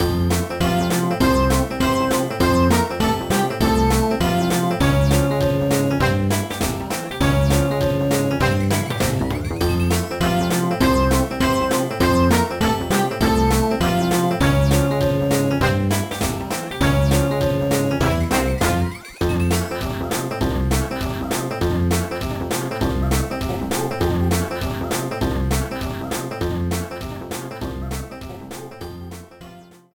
Slideshow music